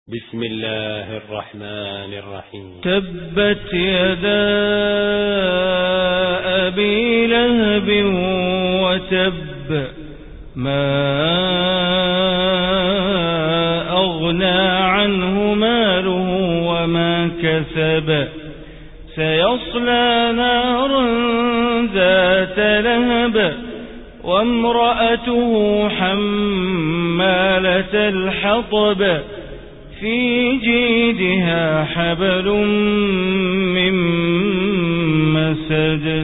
Surat al-Masad Recitation by Sheikh Bandar Baleela
Surat al-Masad, listen online mp3 tilawat / recitation in Arabic recited by Imam e Kaaba Sheikh Bandar Baleela.